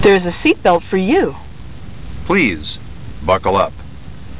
seatbelt.wav